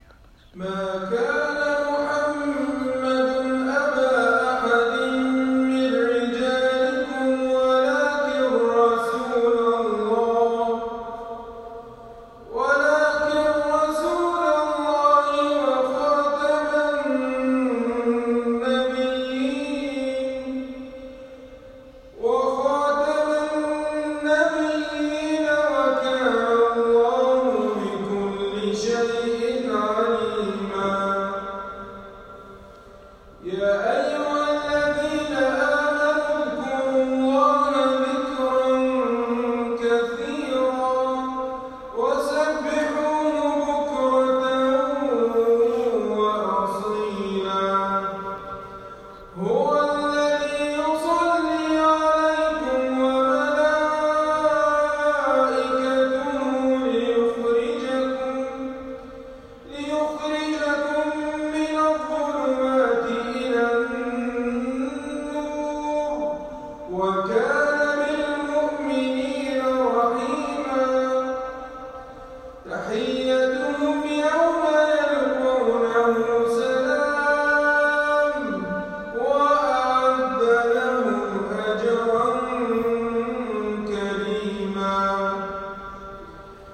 تلاوة هادئة من تسجيلي لأحد الإخوة
تلاوة هادئة من تسجيلي من صلاة العشاء من جامع الإمام أحمد بن حنبل في الجبيل الصناعية لأحد الإخوة التابعين للجمعية الخيرية لتحفيظ القرآن الكريم في 26/4/2019